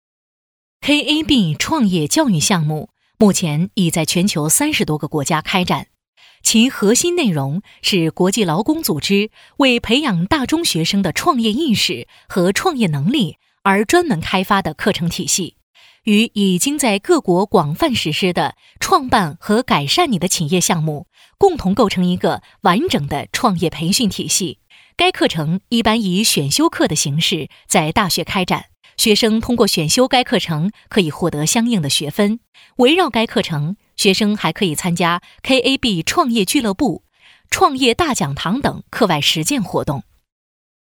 女16-课件教材【KAB 课程教育】
女16-课件教材【KAB 课程教育】.mp3